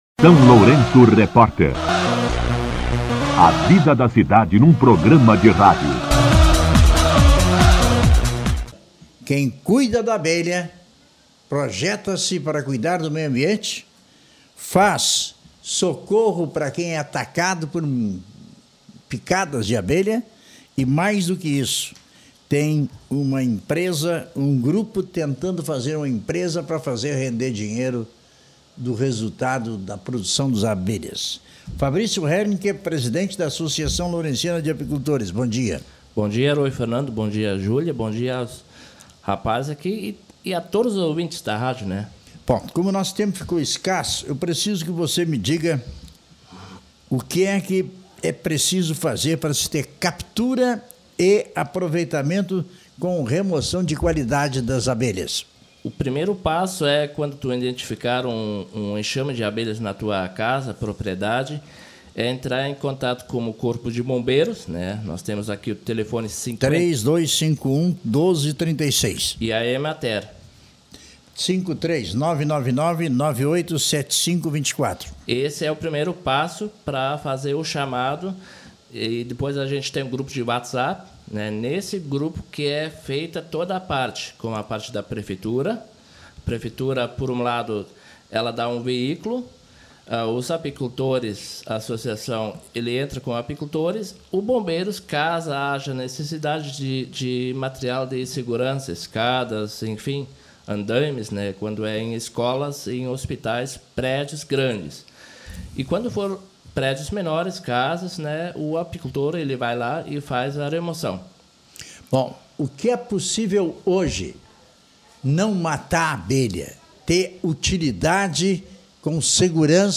Em entrevista ao SLR RÁDIO nesta semana